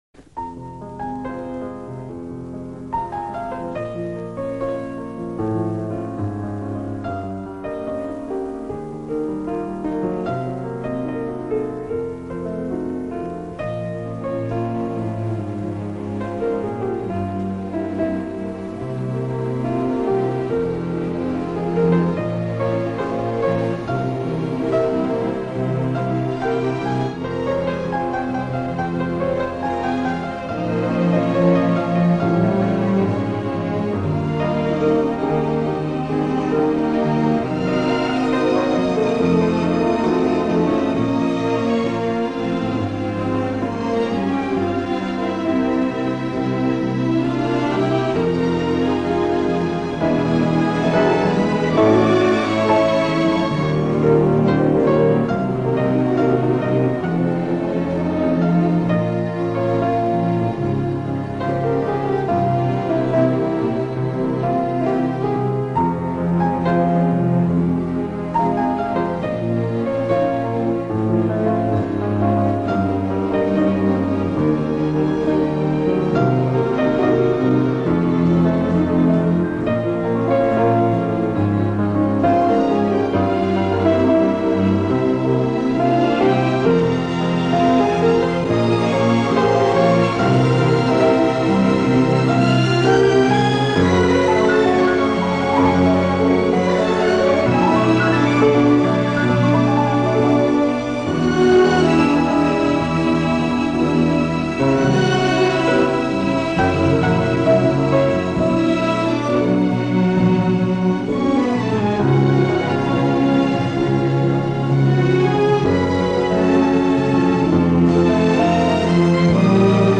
Спасибо,красивая мелодия.